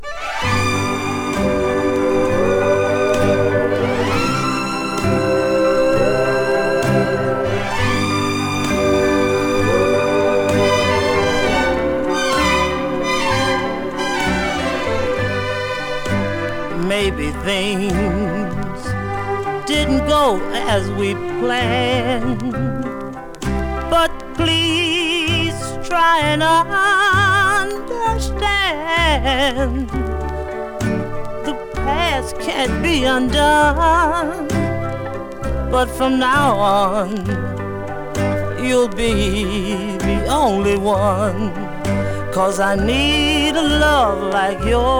ミッドテンポな楽曲にストリングスやコーラス、力強く、伸びのある魅力溢れるハイトーン・テナーボイス。
R&B, Pop, Vocal　USA　12inchレコード　33rpm　Stereo